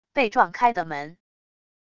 被撞开的门wav下载